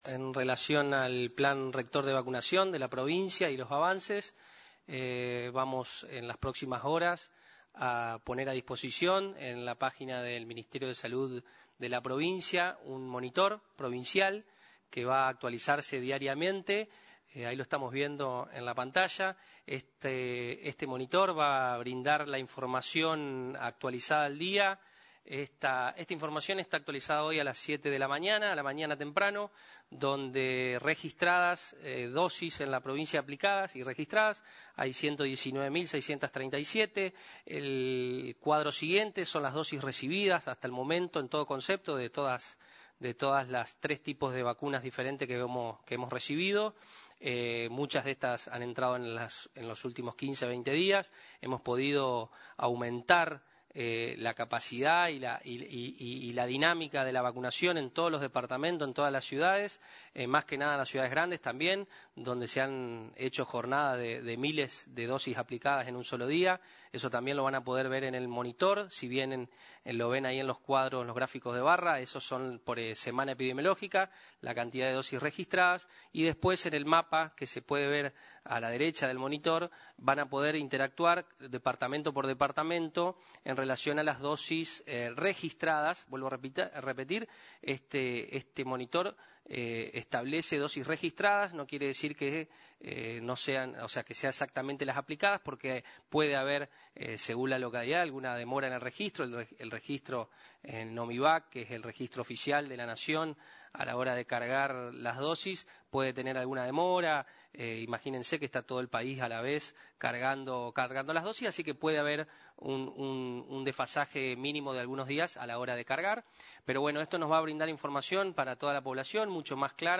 Subsecretario de Redes Integradas de Servicios de Salud ER Marcos Bachetti, en rueda de prensa